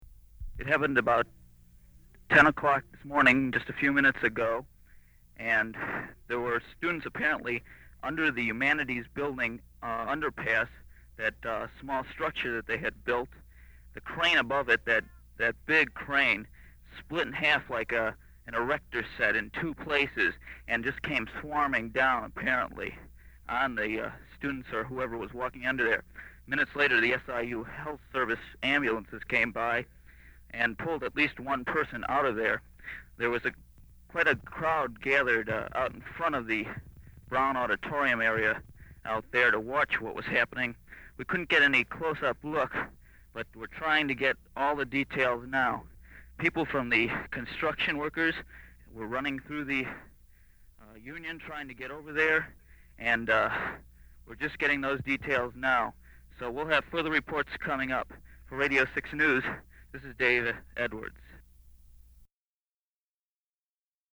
It was aired as a news bulletin at 10:09 am, less than ten minutes after it happened.